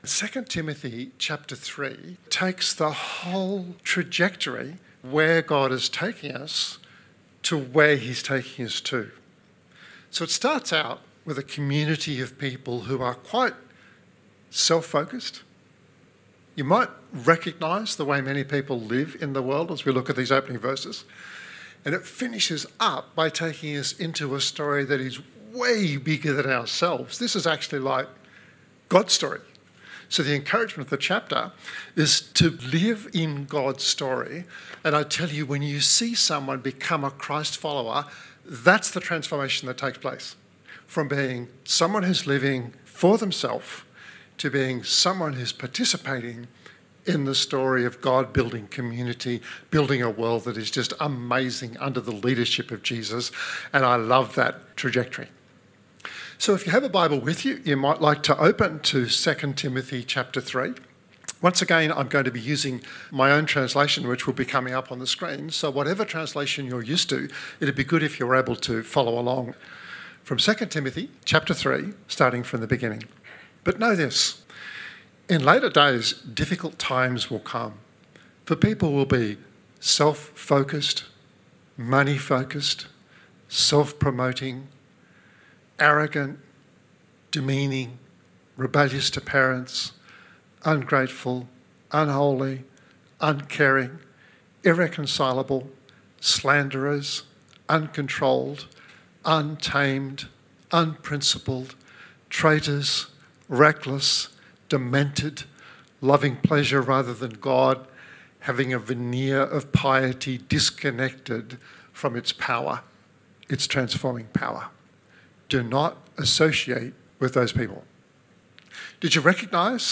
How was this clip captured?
This 23-minute podcast was recorded at Riverview Joondalup 2022-01-23.